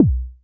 Track 05 - Kick OS 02.wav